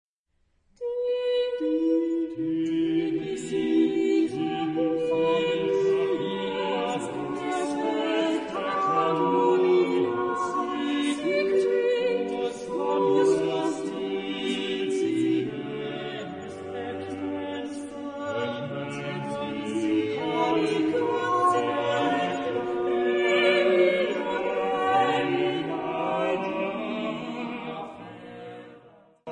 Epoque : 16ème s. (1550-1599) Genre-Style-Forme : Profane ; Madrigal Caractère de la pièce : solennel Type de choeur : SATB (4 voix mixtes )
Tonalité : sol mode de ré